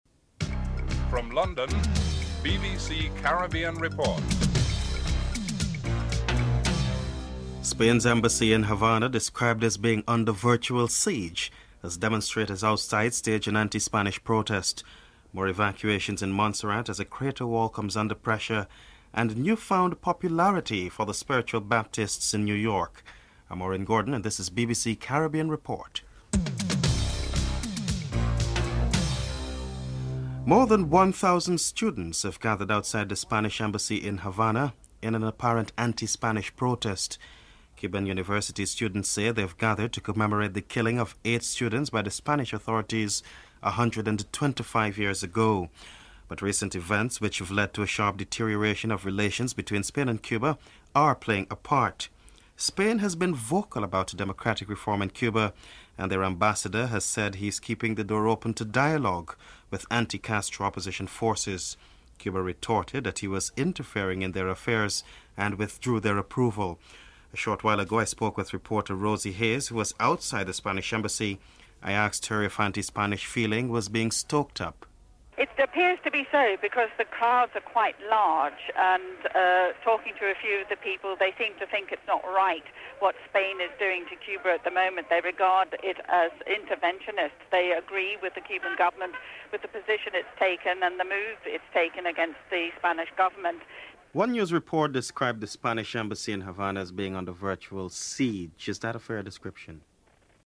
5. The American Ambassador in Port of Spain says his country is not trying to impose its views on Trinidad and Tobago or any other Caribbean country concerning the Boutros-Ghali reelection issue at the United Nations. Ambassador Dr Brian Donnelly is interviewed (10:33-12:11)